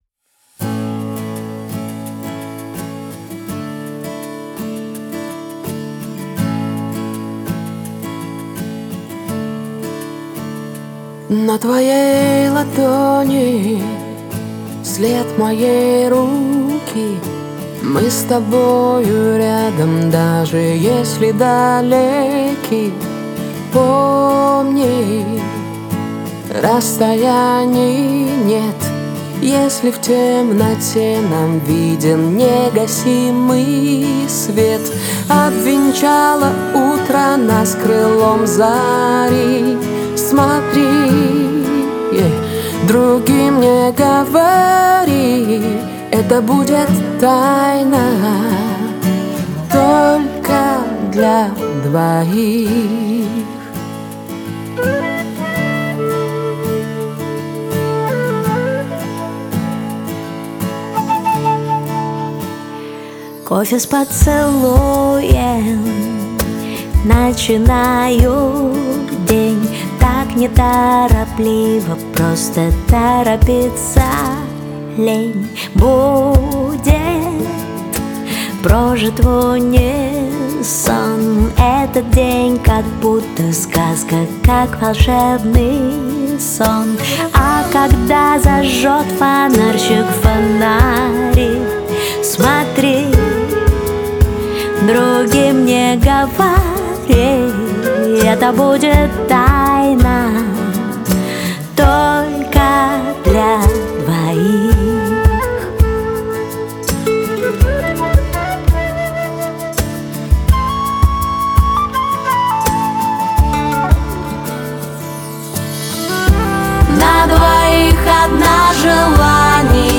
это романтическая баллада в жанре поп